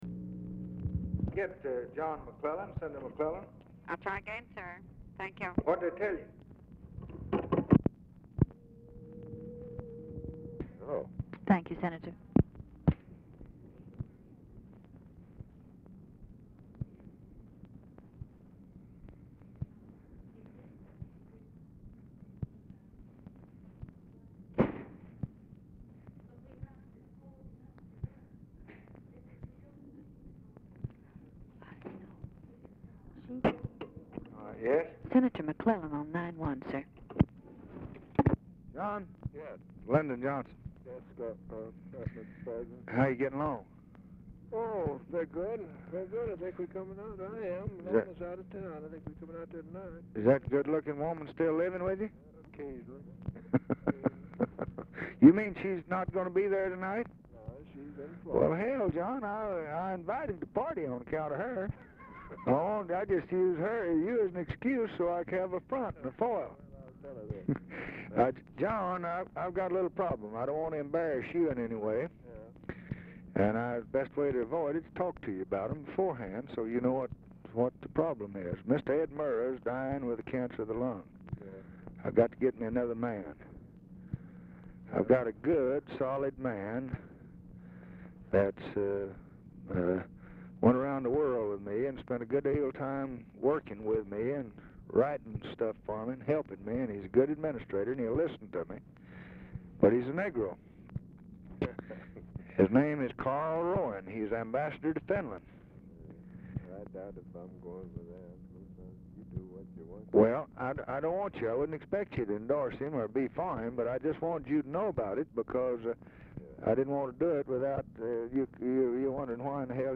Telephone conversation # 1386, sound recording, LBJ and JOHN MCCLELLAN, 1/16/1964, 4:20PM | Discover LBJ
Format Dictation belt
Location Of Speaker 1 Oval Office or unknown location